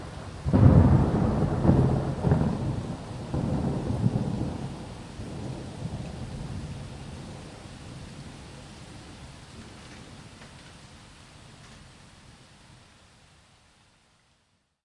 纽约市暴雨 " NYC Rain 4 A闪电裂缝
描述：纽约雨风暴;一些交通噪音在背景中。雨在街道，植物，外部家。大雷，警笛。 用Zoom H1记录; 2012年7月;纽约，美国。
Tag: NYC 风暴 天气 闪电 警报器